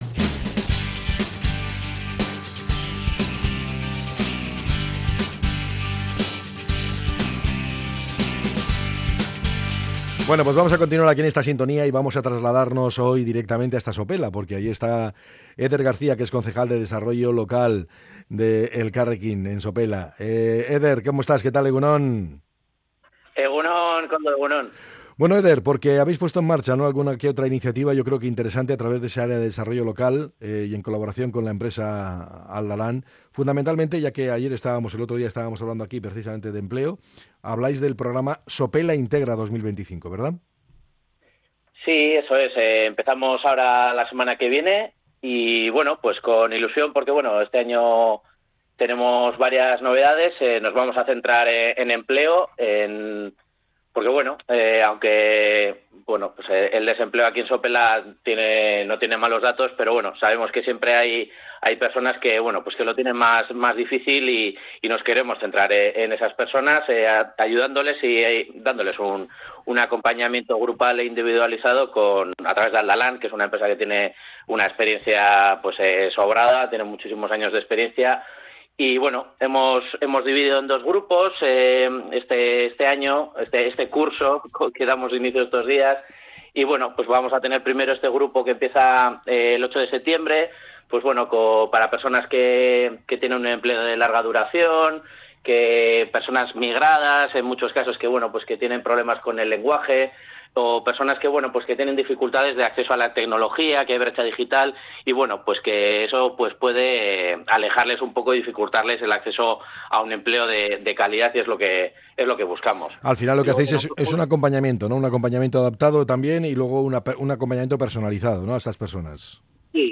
El concejal de Desarrollo Local de Sopela, Eder García, ha detallado en EgunOn Bizkaia las iniciativas que el Ayuntamiento ha puesto en marcha para este curso en materia de empleo, emprendimiento y apoyo al comercio local.